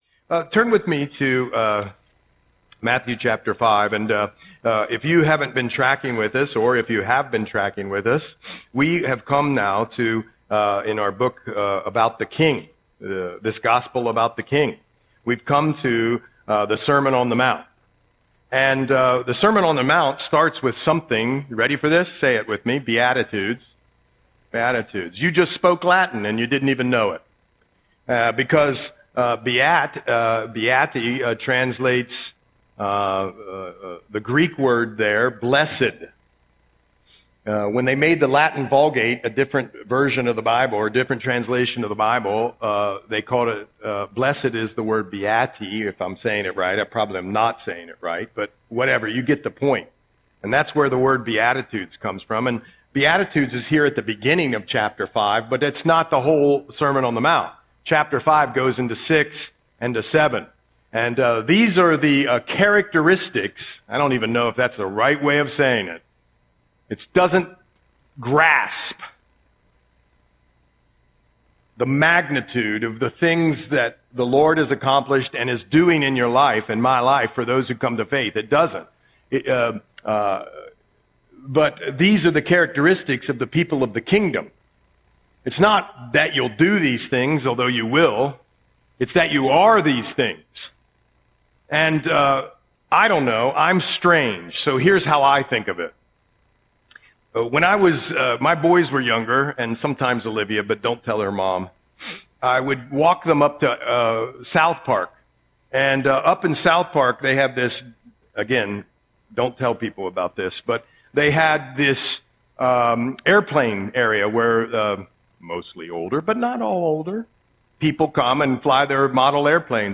Audio Sermon - November 16, 2025